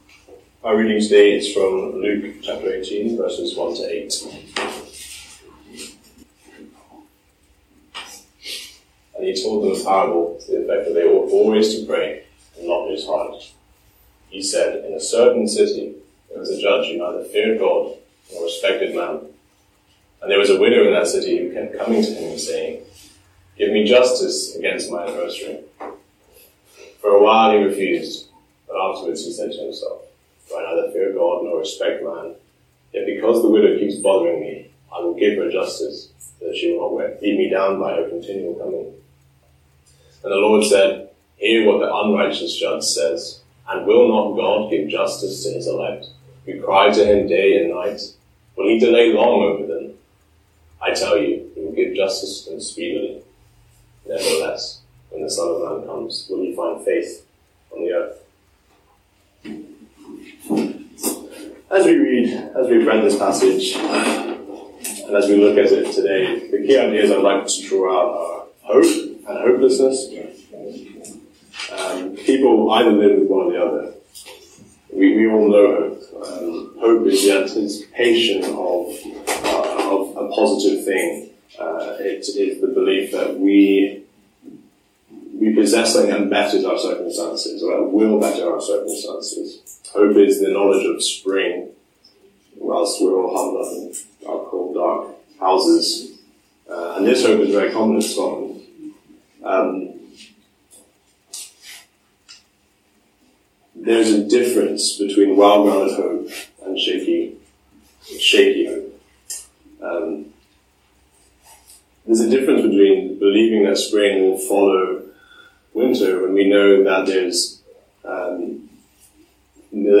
A link to the video recording of the 11:00am service and an audio recording of the sermon.
Series: Individual sermons